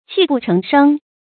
泣不成聲 注音： ㄑㄧˋ ㄅㄨˋ ㄔㄥˊ ㄕㄥ 讀音讀法： 意思解釋： 泣：低聲哭。